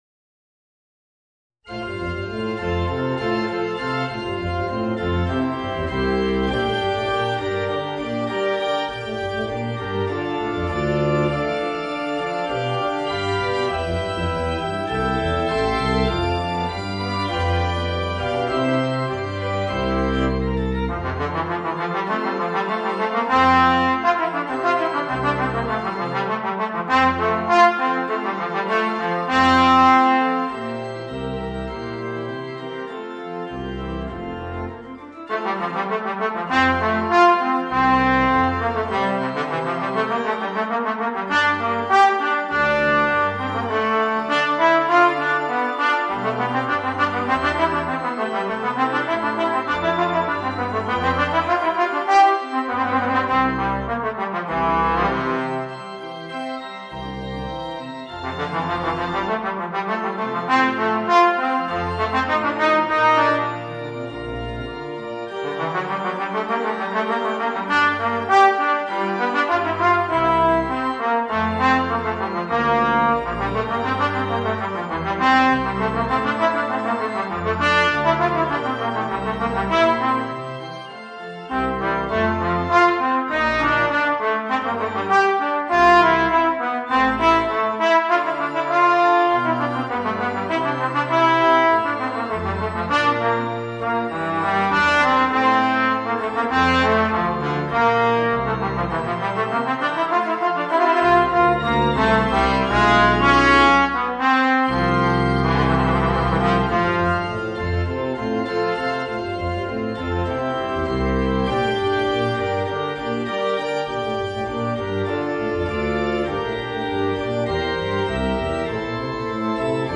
Voicing: Trombone and Organ